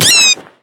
mob / bat / death.ogg
death.ogg